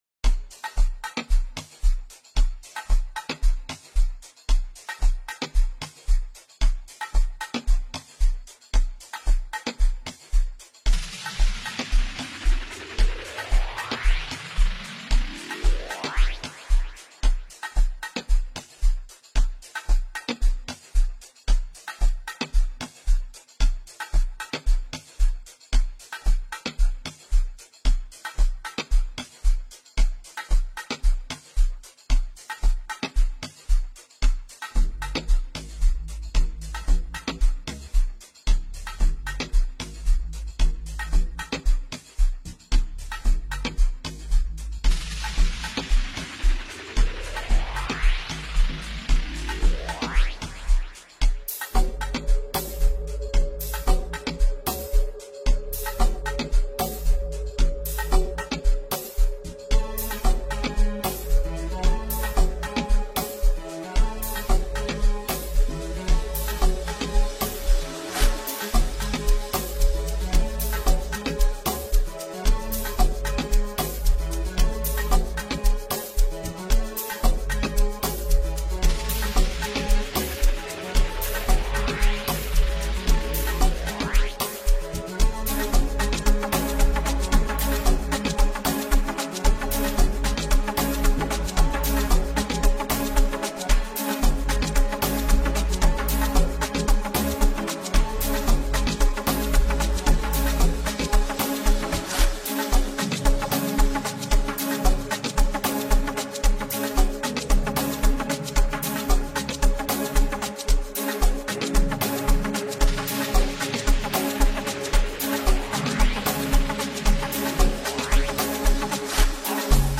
heavy new banger
Amapiano
instrumental offering